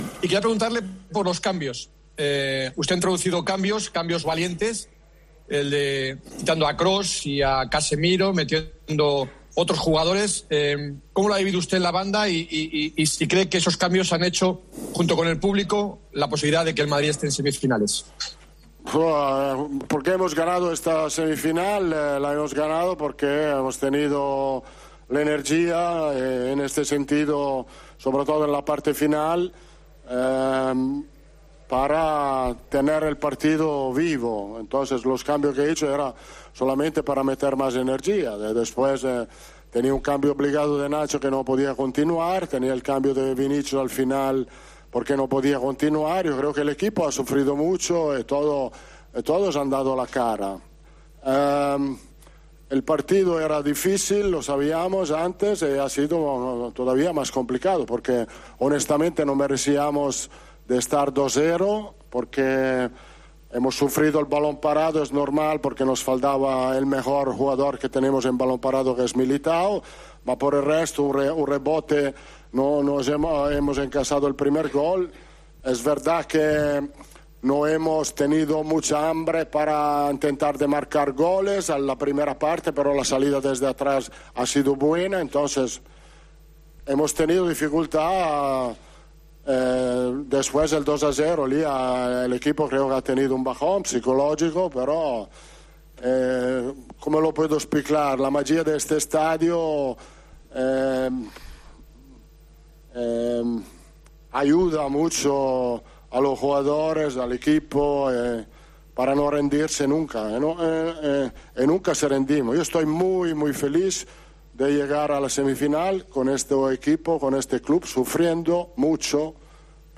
EN RUEDA DE PRENSA